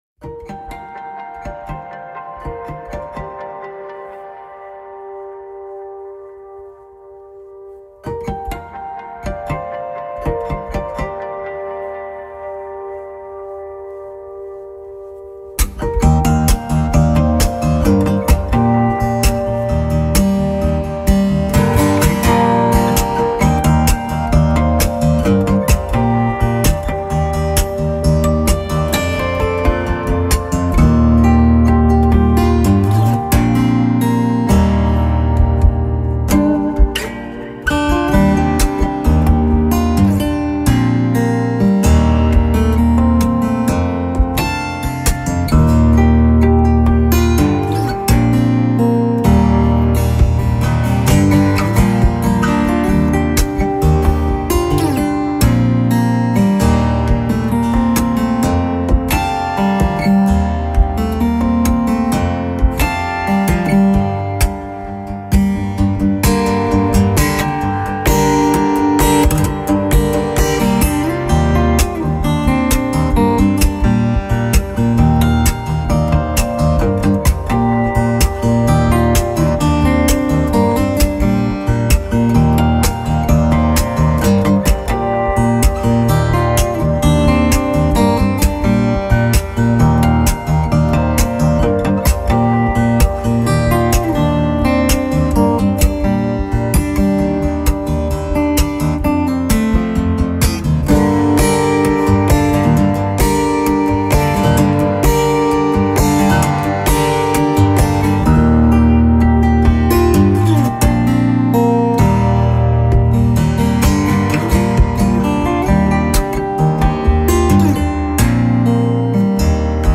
instrumental cover
Tags2010s 2015 acoustic Canada cover songs